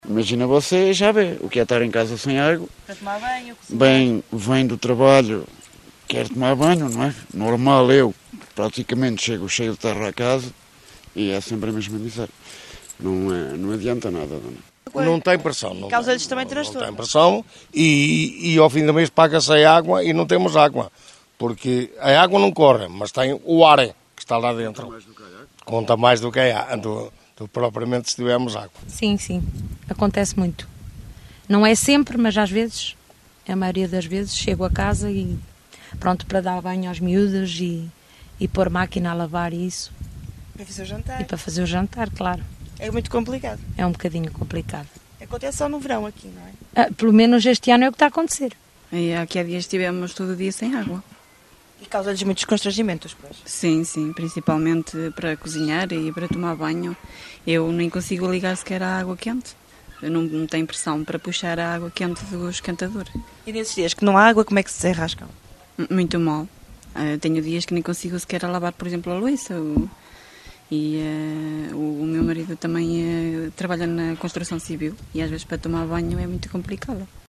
Ao final da tarde, nas ruas do bairro, a situação é reportada pelos moradores.
rd_-vox-pop-água-1.mp3